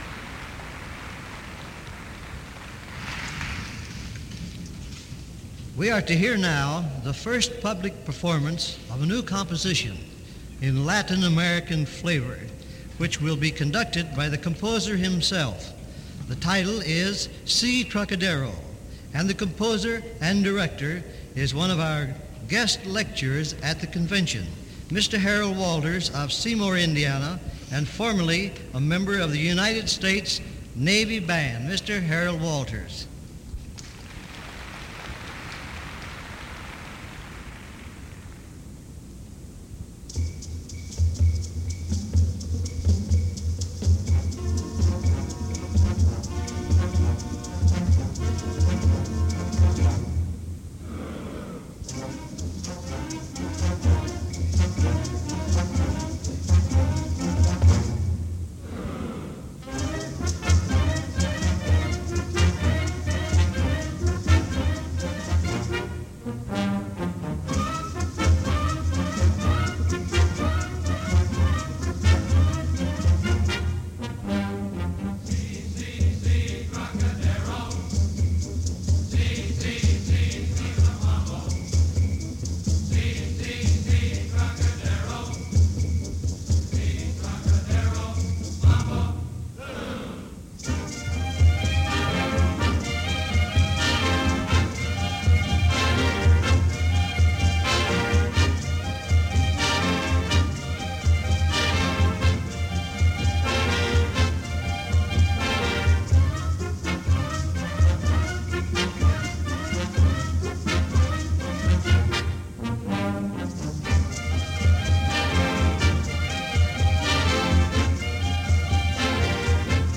These mp3 files are from a CD that was a digital transfer from a reel-to-reel tape. It is a recording of radio WHO’s broadcast of the Iowa Bandmasters Association “Directors Band” that played at the 1951 convention in Des Moines.